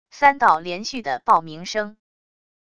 三道连续的爆鸣声wav音频